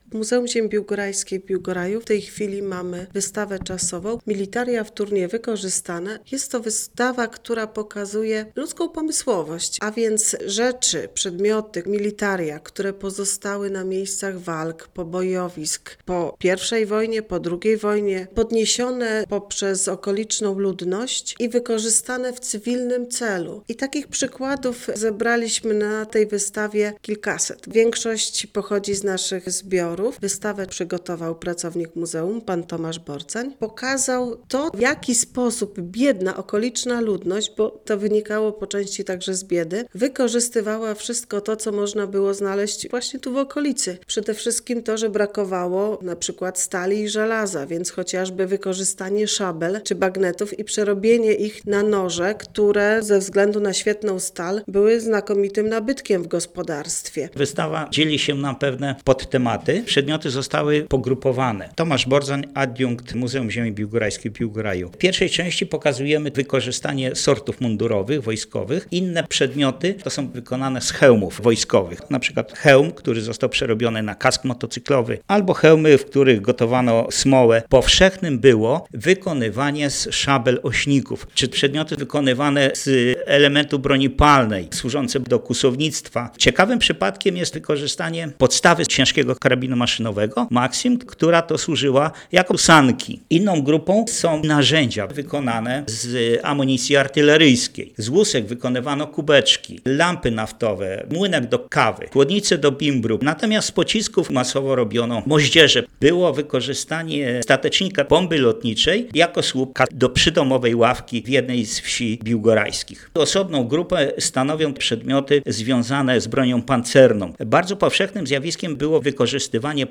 militaria_wtornie_wykorzystane_audycja.mp3